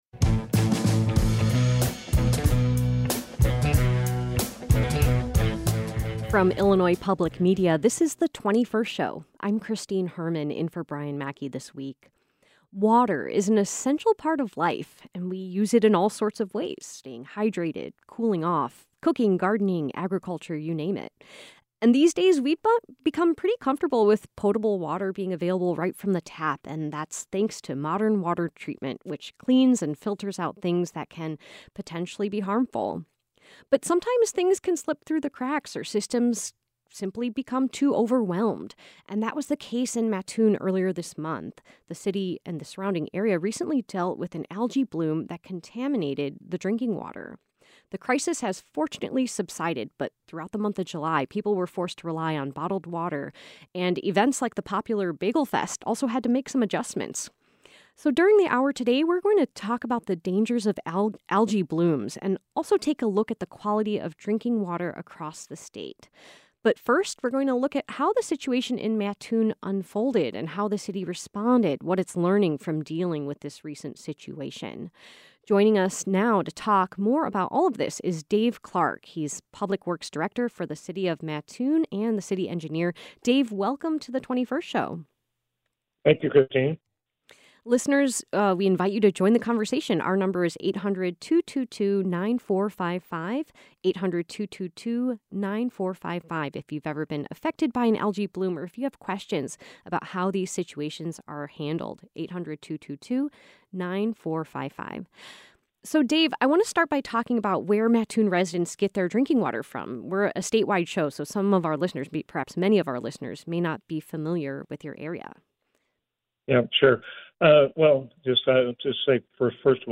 We speak with an expert on water resources to learn more.